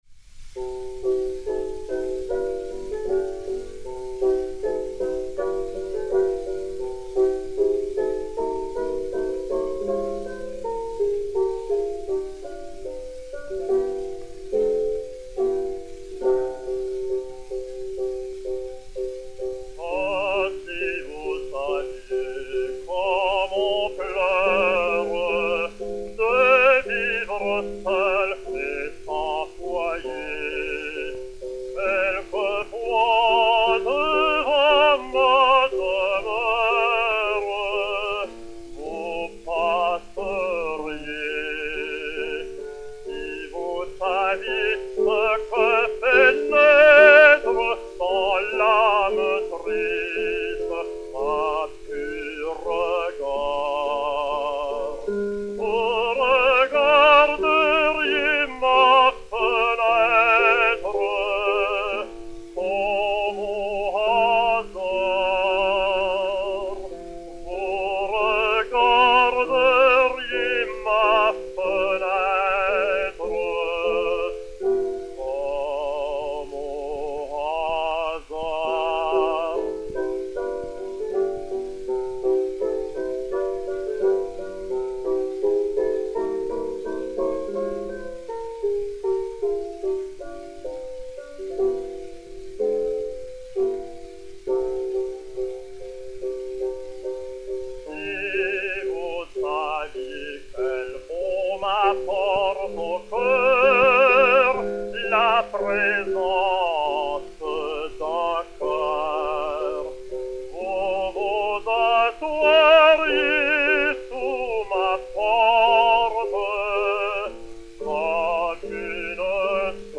Mélodie
enr. vers 1909